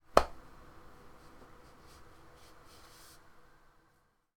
shouldergrab.wav